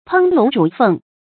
烹龙煮凤 pēng lóng zhǔ fèng 成语解释 见“烹龙炮凤”。